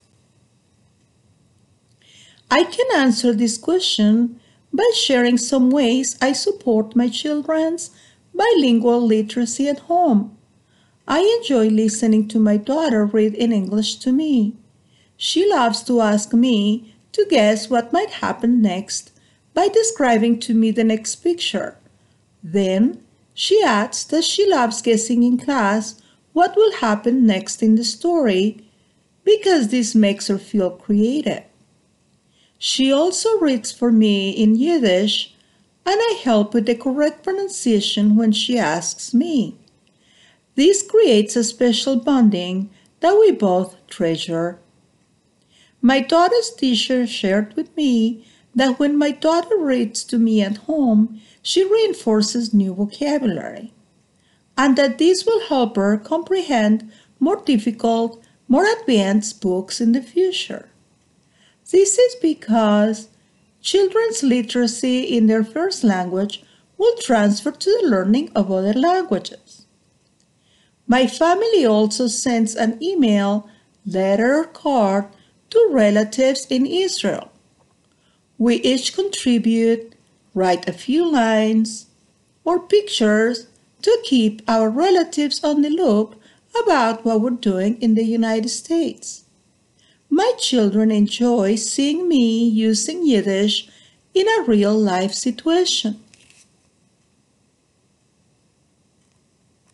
[Note: In the transcript below, ellipses indicate that the speaker paused.]
The response effectively communicates clear and logically sequenced ideas delivered with a consistent flow of speech, few pauses, intelligible pronunciation, and appropriate intonation.
Examples of such errors include some inconsistency in the flow of speech and few pauses.